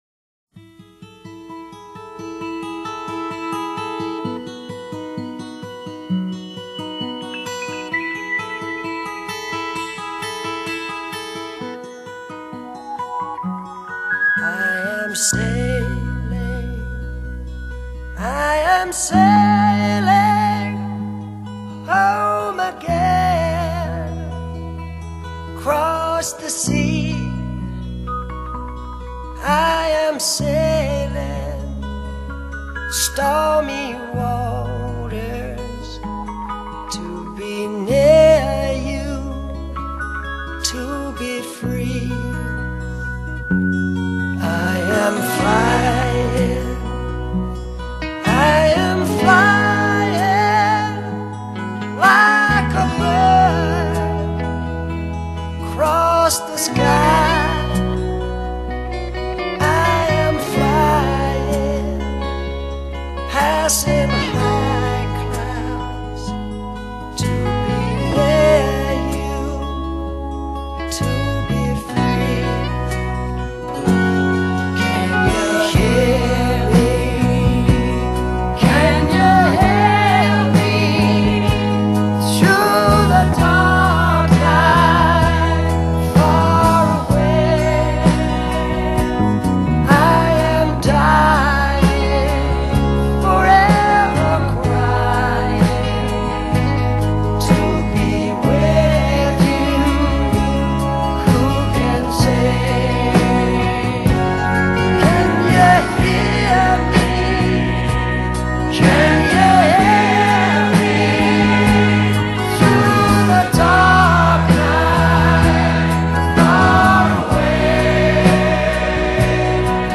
风格：流行摇滚 成人摇滚